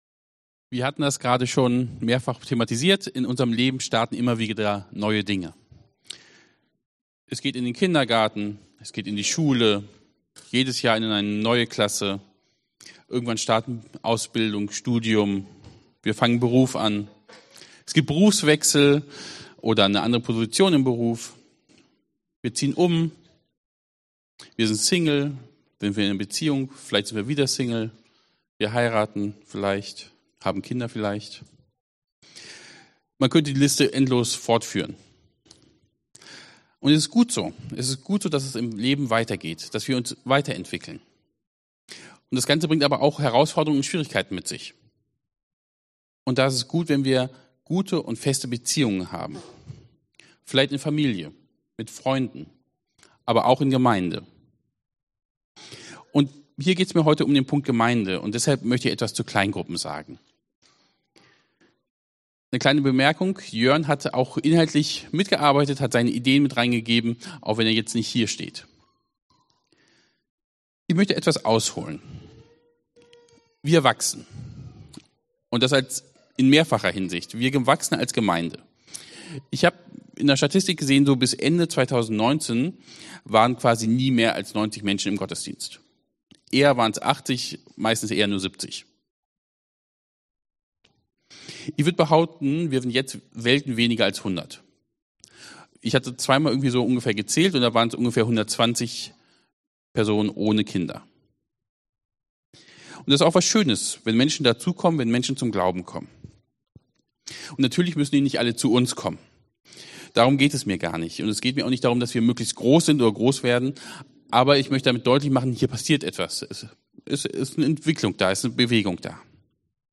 Serie: Dein Sommer mit Gott Dienstart: Predigt Themen